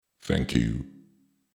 알림음 8_Robot_Thankyou.mp3